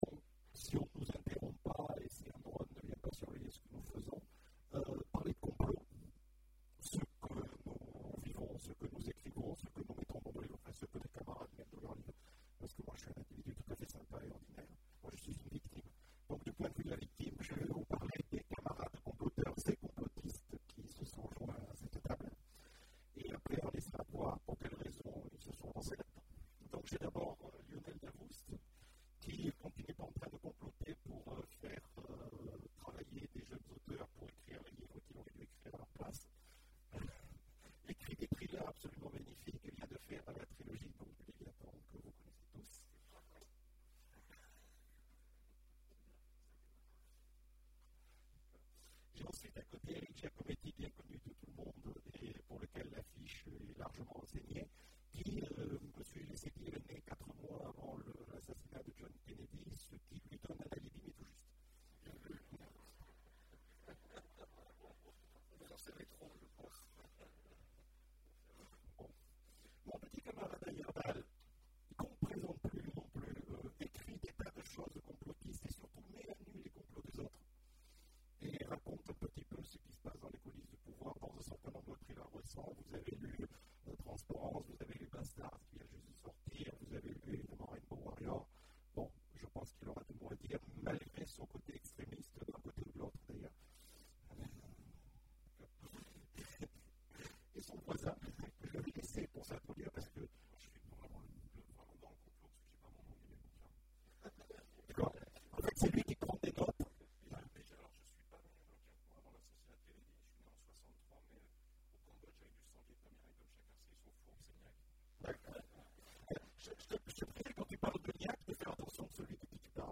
Imaginales 2014 : Conférence Complots !